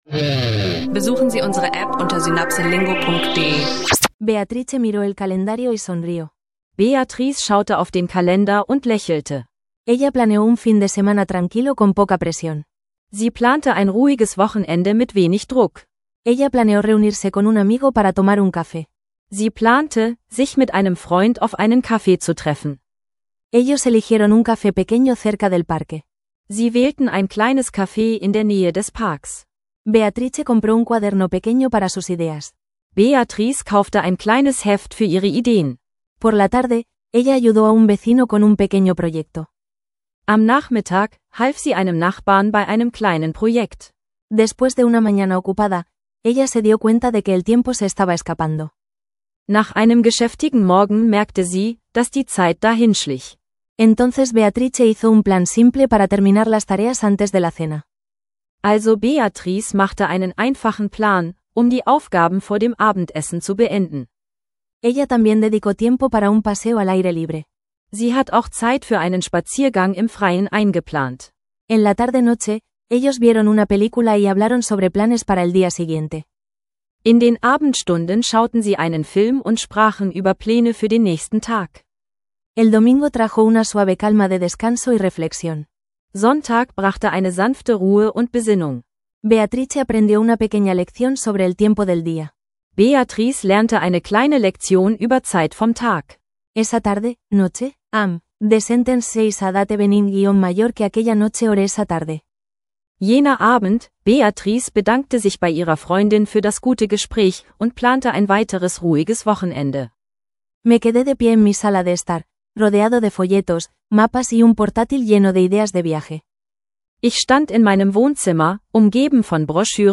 Lerne Spanisch mit leicht verständlichen Dialogen über Zeitplanung und Reiseplanung – perfekt für Anfänger und Fortgeschrittene.